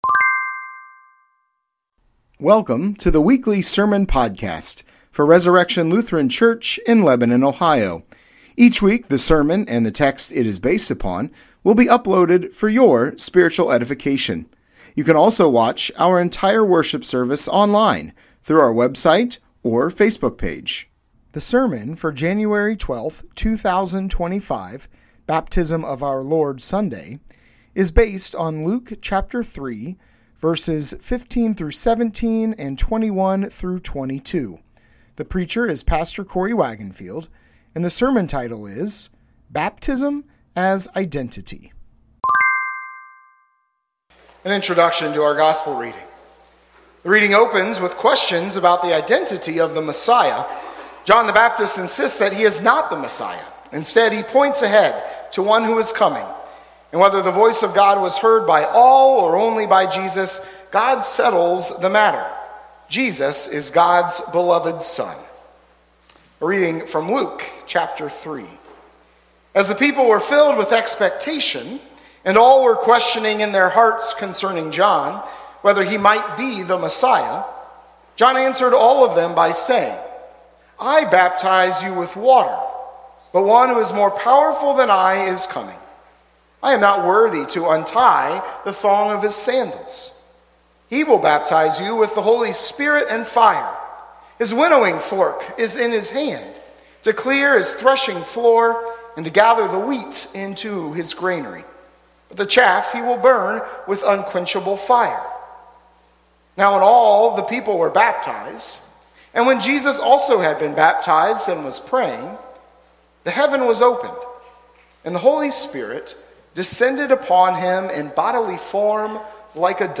Sermon Podcast Resurrection Lutheran Church - Lebanon, Ohio January 12, 2025 - "Baptism As Identity"